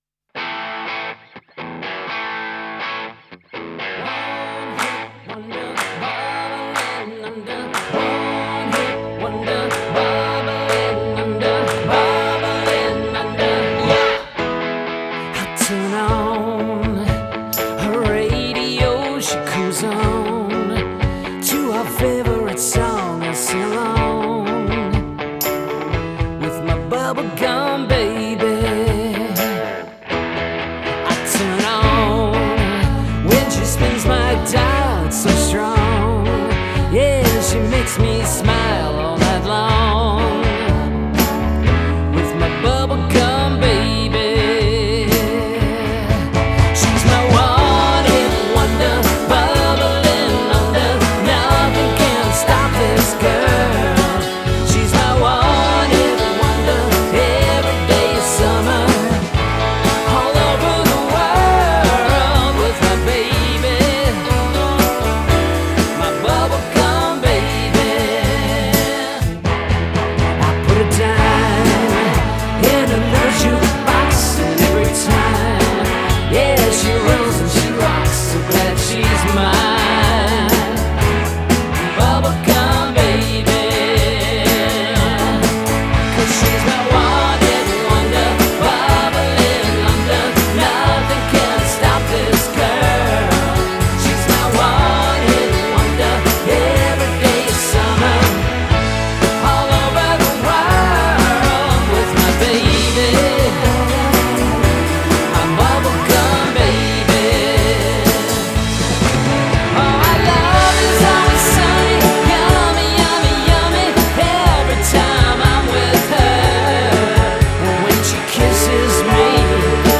There’s still a strong easy listening pop vibe to the album
breezy Shaun Cassidy-esque